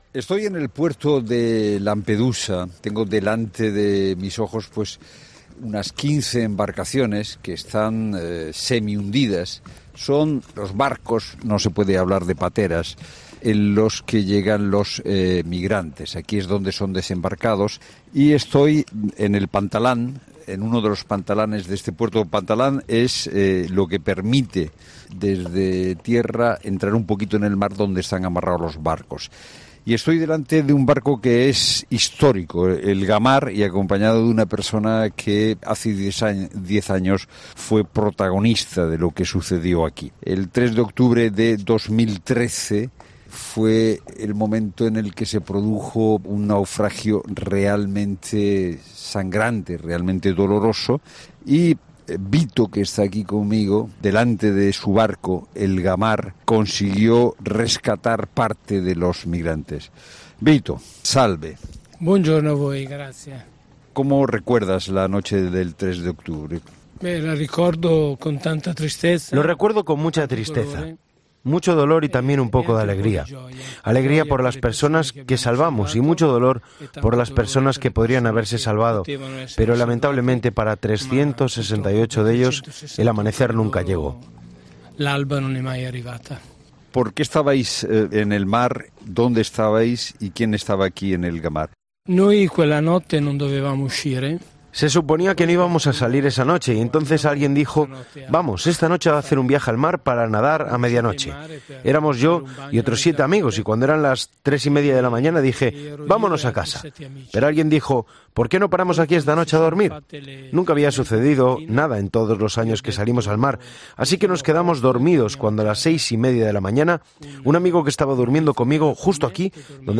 "Empezaba a amanecer y le dije que eran gaviotas, pero él insistió en que escuchó estos gritos. Navegamos 800 metros y los encontramos. 200 personas frente a nosotros gritando y pidiendo ayuda" relata con muchísimo dolor.
"Lo recuerdo con mucha tristeza, dolor y a veces alegría por las personas que salvamos, y dolor por las que podían haberse salvado. Para 368 de ellos el amanecer no llegó" decía con dolor.